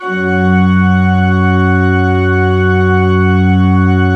Index of /90_sSampleCDs/Propeller Island - Cathedral Organ/Partition I/MAN.PLENO R